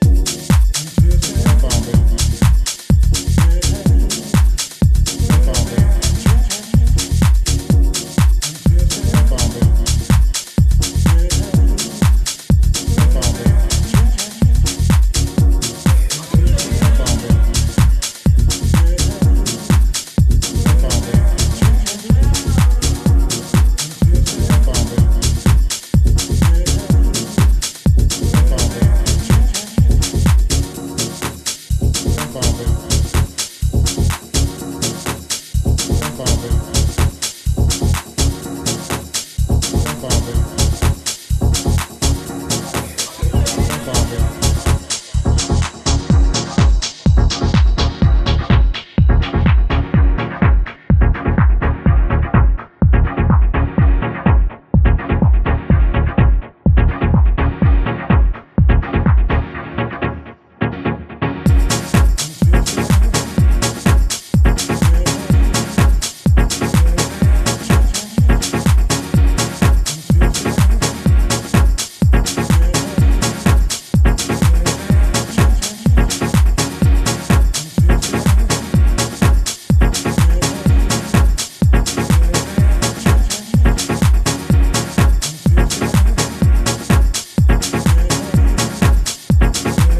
ジャンル(スタイル) DEEP HOUSE / HOUSE